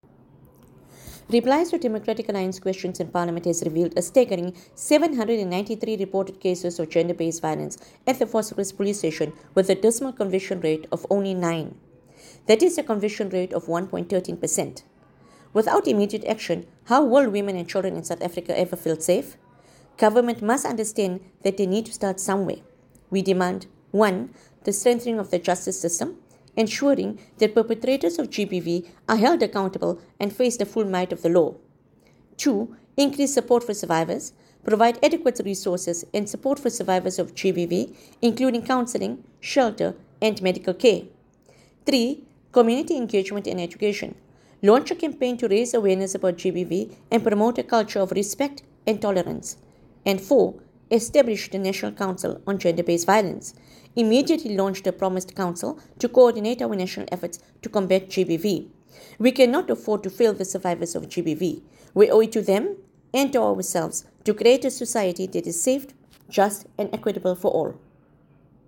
Note to Editors: Please find an English soundbite by Haseena Ismail MP
Haseena-Ismail-MP_ENG_Vosloorus-GBV-Convictions.mp3